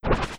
Hit & Impact
Hit8.wav